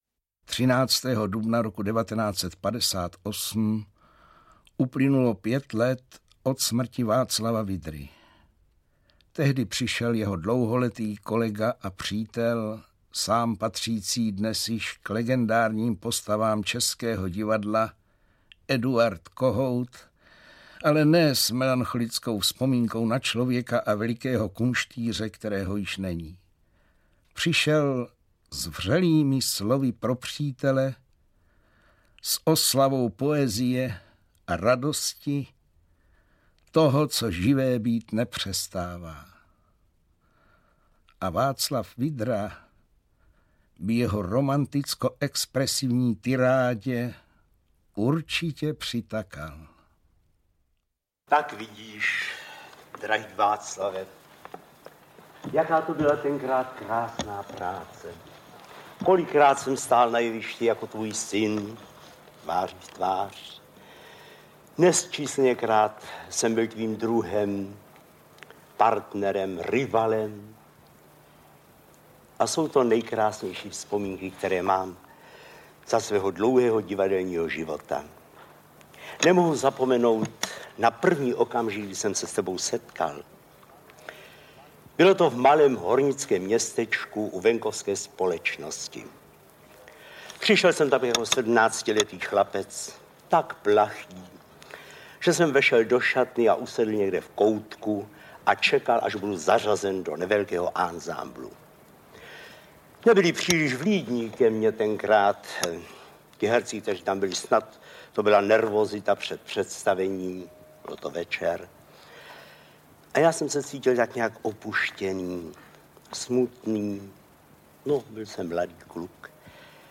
Audiokniha
Zajímavé vzpomínky, autentické hlasy, obojí hodno zachování - dokumenty doby na druhou!